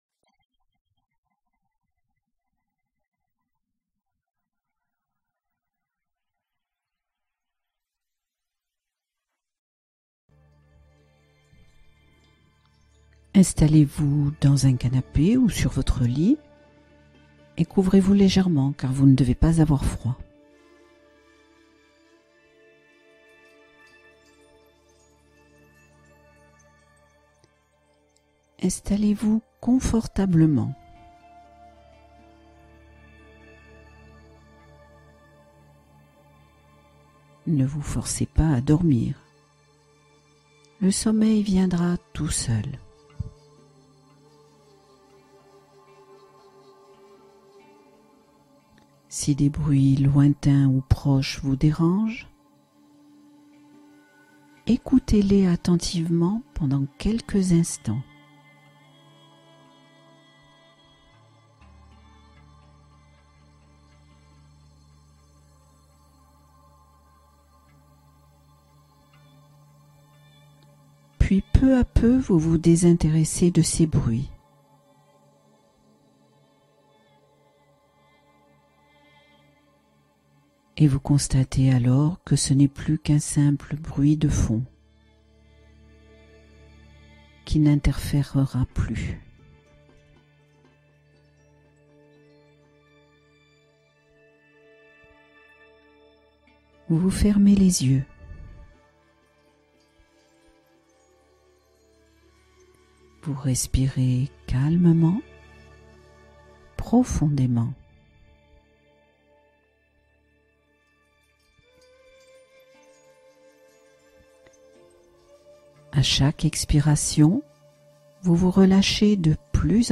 Relaxation guidée : sommeil réconfortant et profond